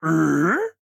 AV_bear_question.ogg